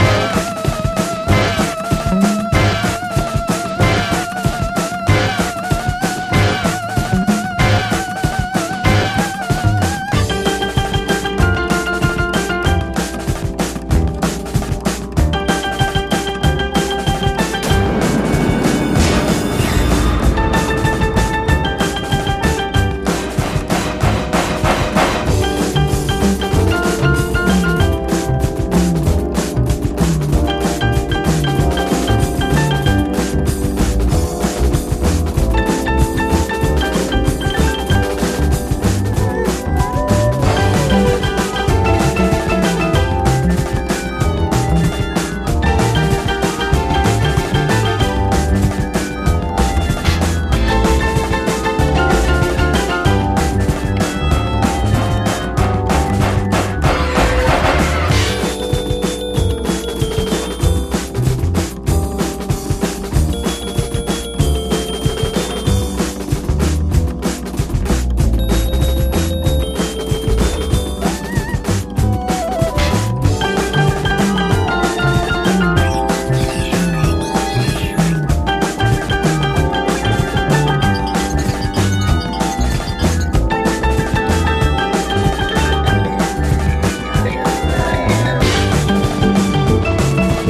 NOISY INSTRUMENTAL